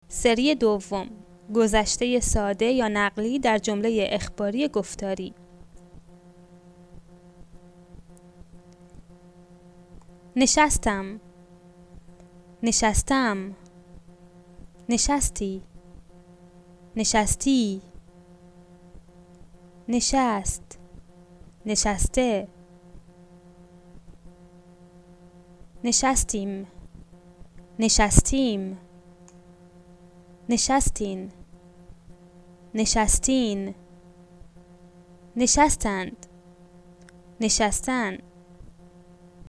The Present Perfect is stressed on the last syllable and the Simple Past on the penultimate syllable.